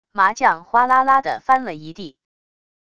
麻将哗啦啦的翻了一地wav音频